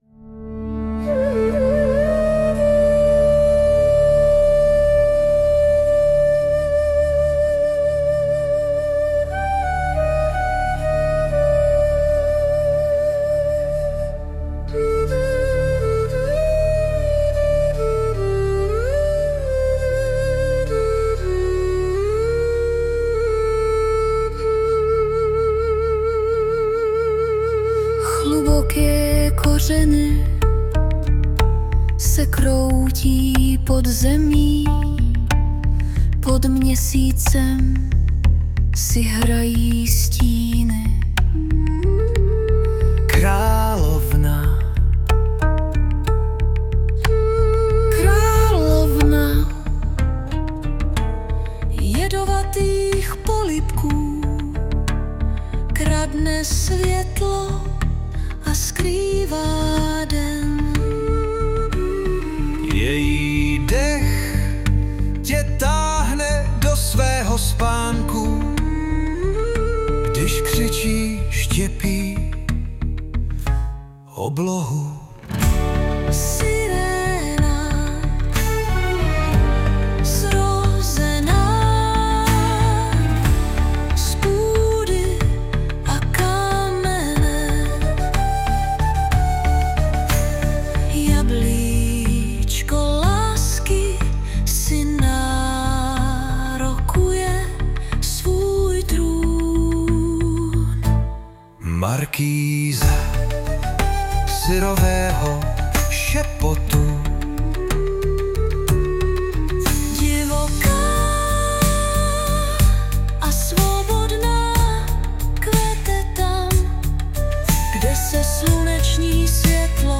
2025 & Hudba, zpěv a obrázek: AI